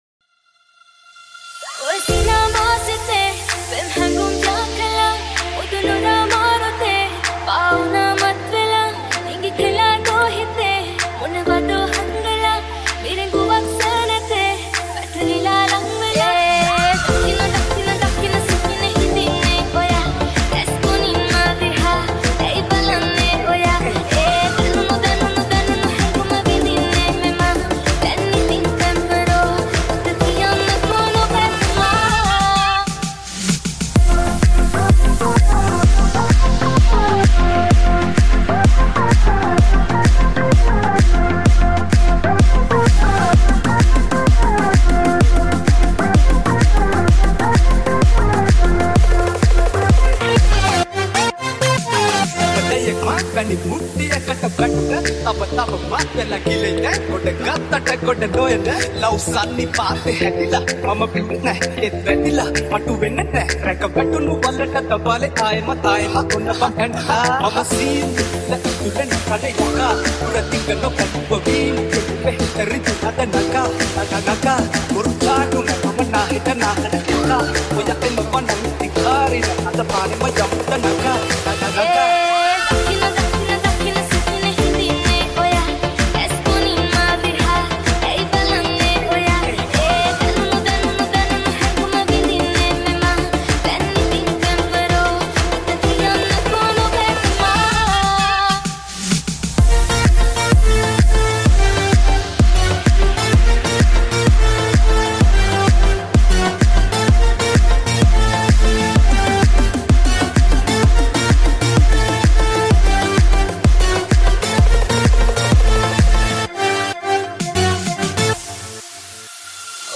Sinhala Remix Songs